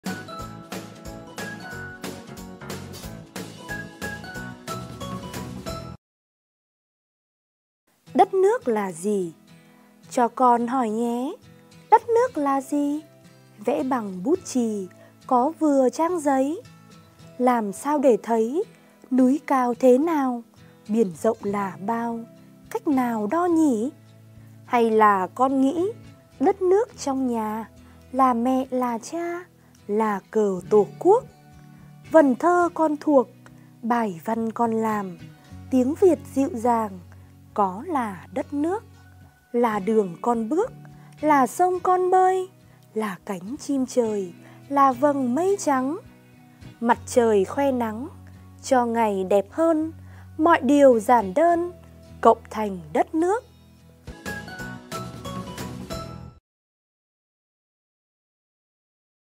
Sách nói | Đất nước là gì ?